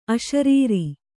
♪ aśarīri